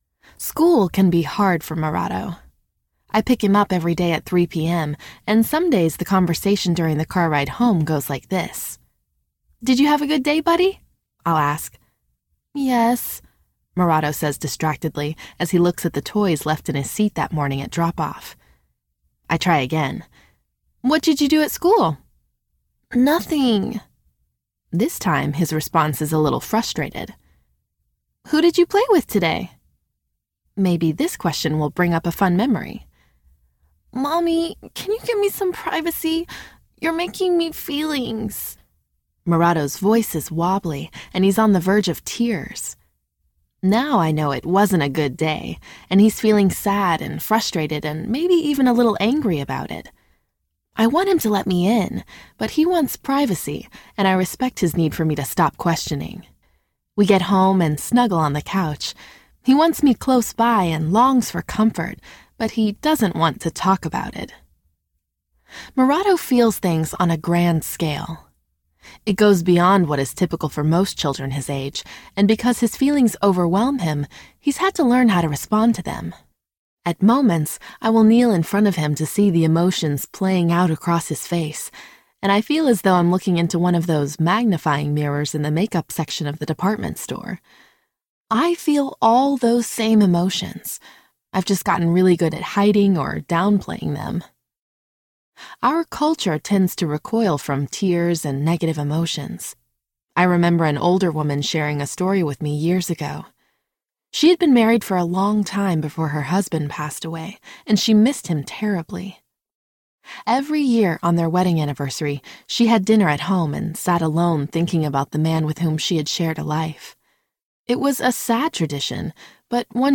It’s Okay About It Audiobook
5.72 Hrs. – Unabridged